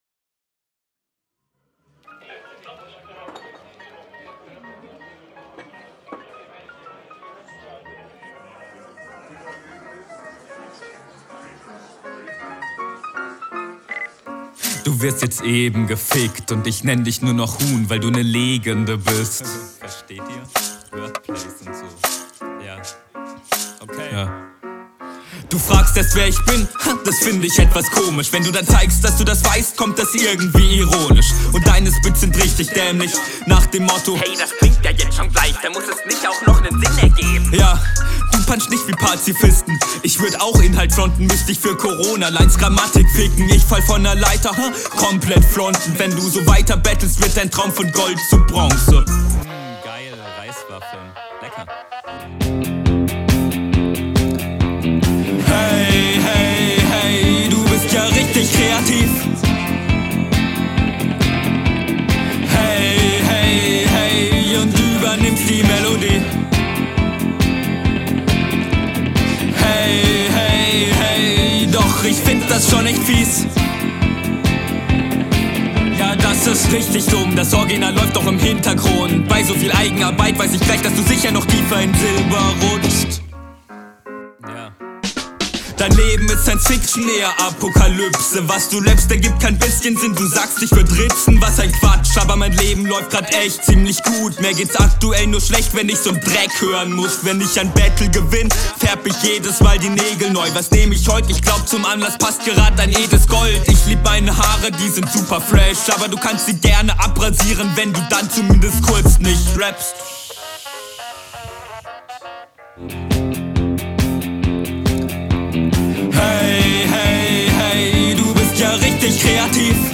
Hast gut gekontert Flow auf einem ähnlichen Level Reime fand ich minimal besser Leg Henne …
an sich sehr cool aber hook klingt hart verschoben :((